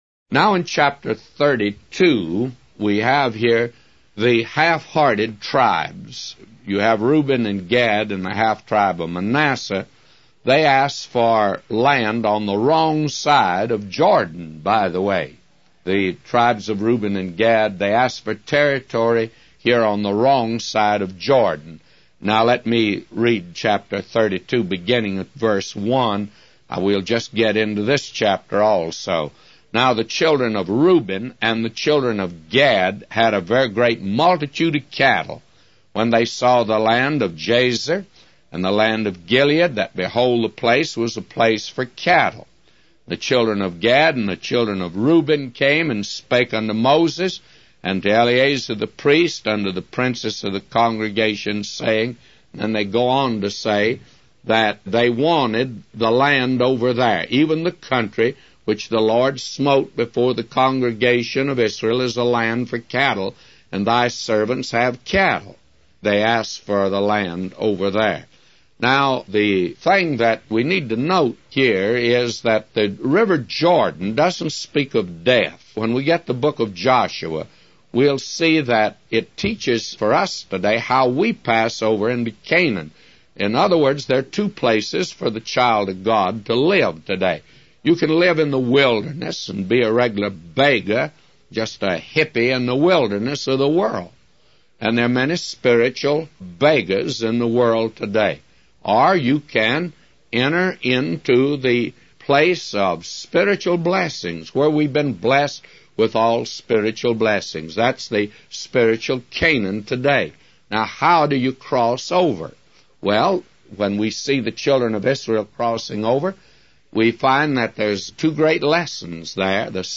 A Commentary By J Vernon MCgee For Numbers 32:1-999